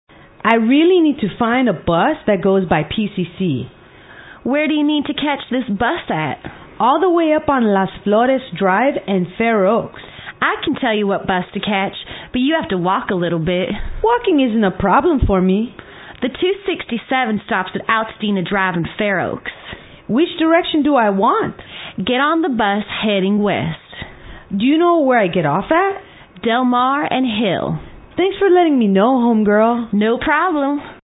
乘车英语对话-Which Bus to Take(1) 听力文件下载—在线英语听力室